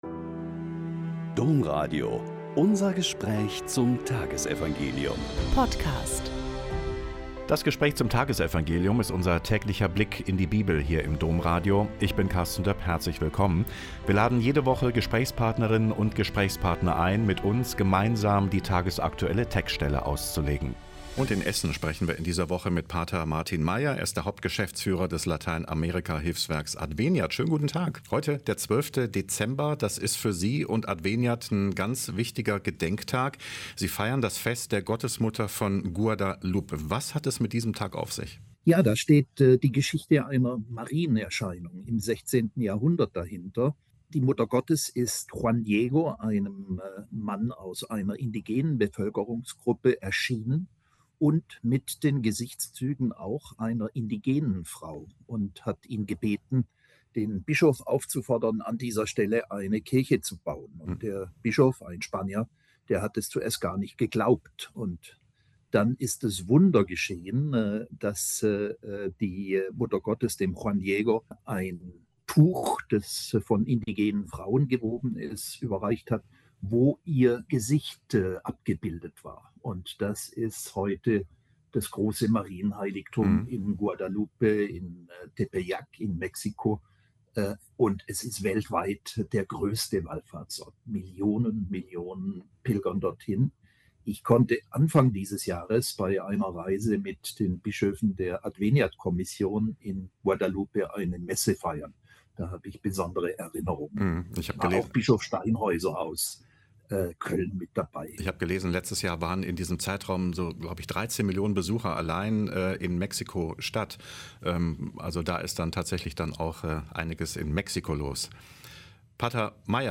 Mt 11,16-19 - Gespräch